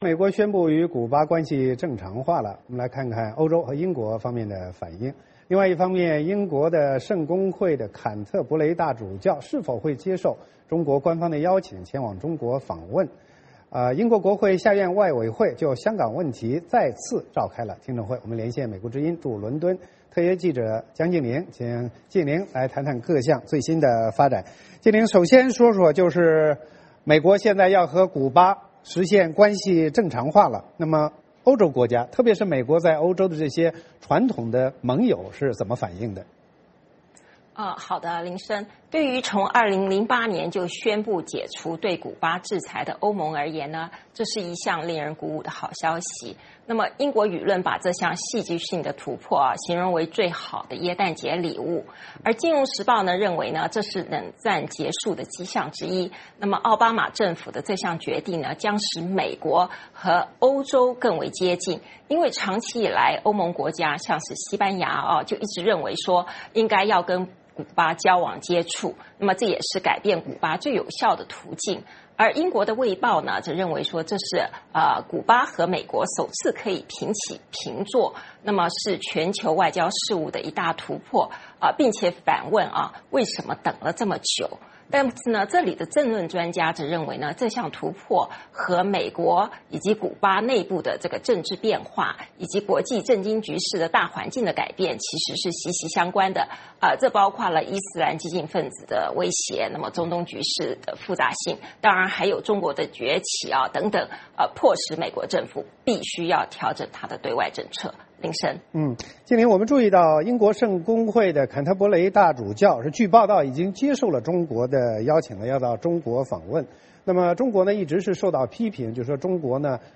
VOA连线：欧洲对美古关系正常化的反应